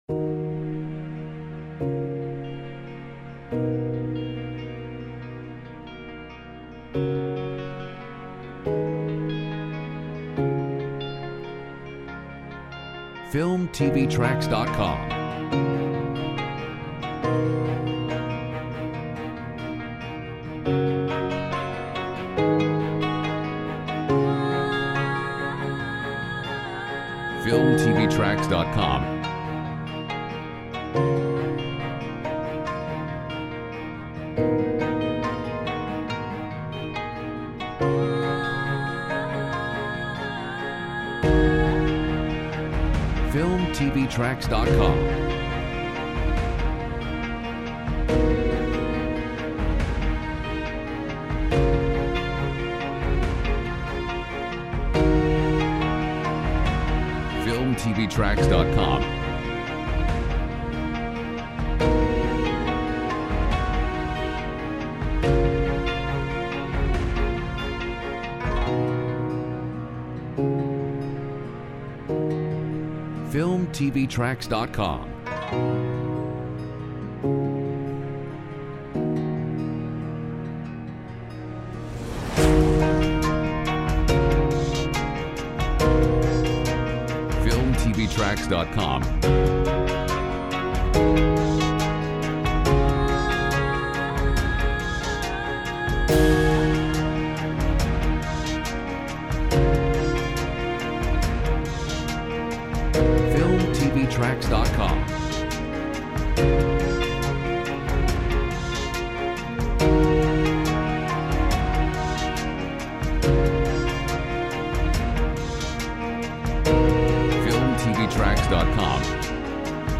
Genres: Piano, Orchestral
Mood: Calm, inspiring, uplifting, emotive